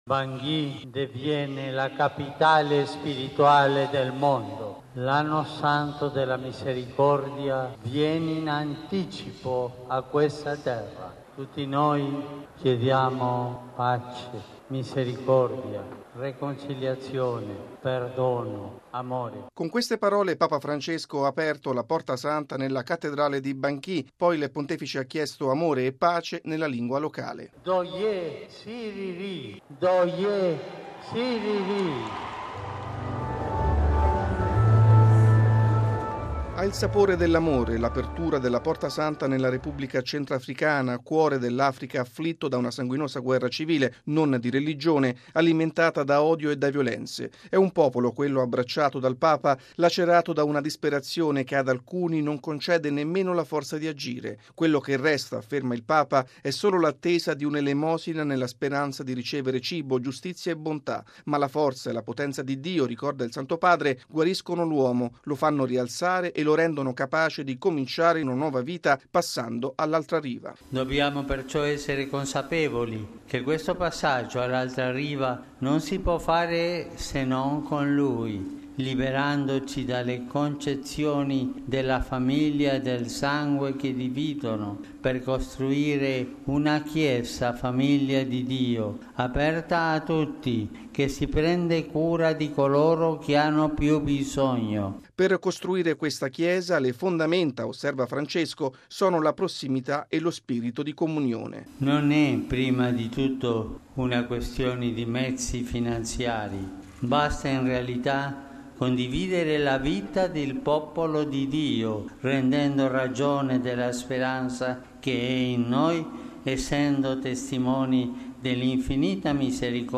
Il Santo Padre ha aperto la Porta Santa e poi è entrato per primo nella Cattedrale di Bangui, dove ha celebrato la Messa.